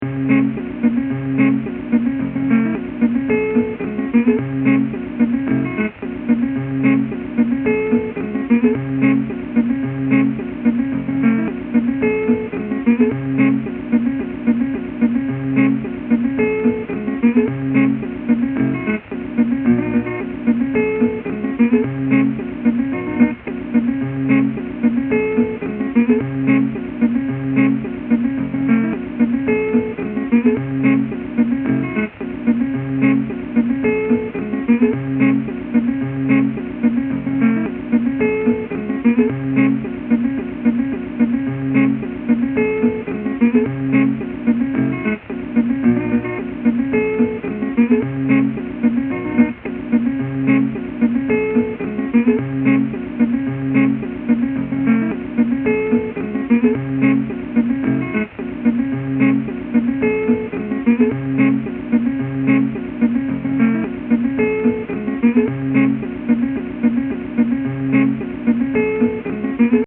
The following audio files have been created using tracks from Open Music Archive.
Loop Five